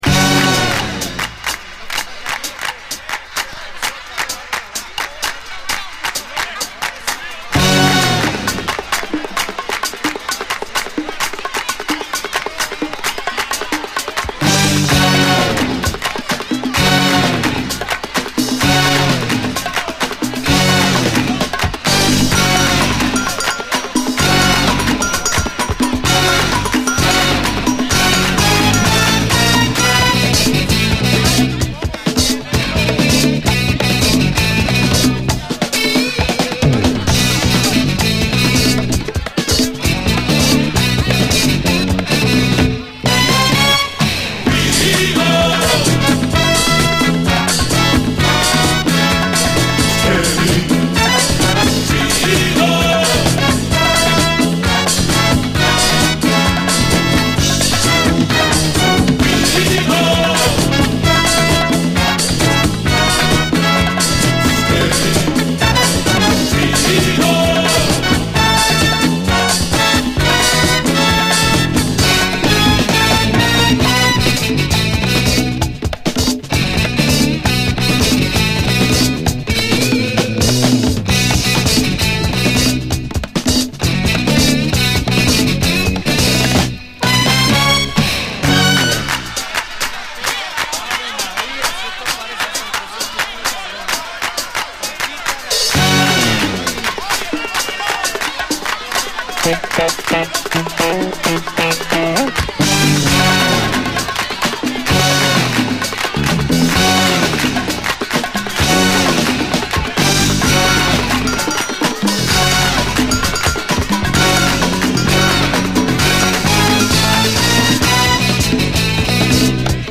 SALSA, LATIN
エレピが涼しげなメロウ・サルサ